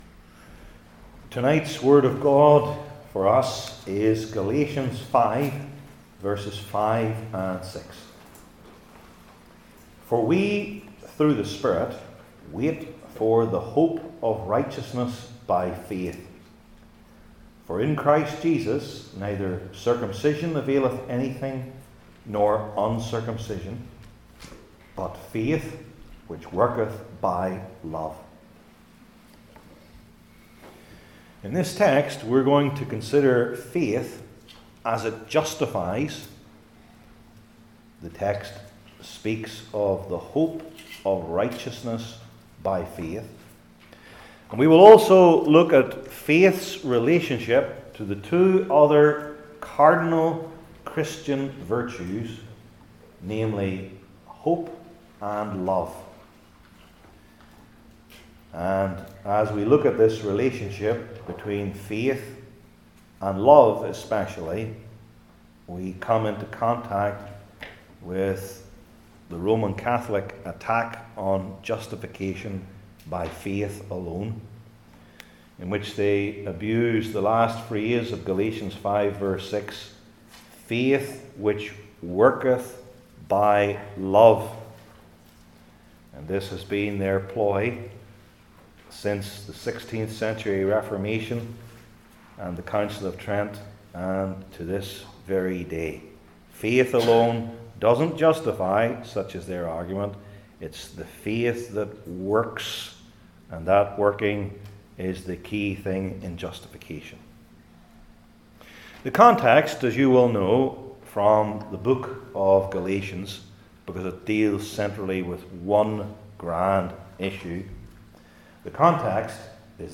New Testament Individual Sermons I. Faith and Righteousness II.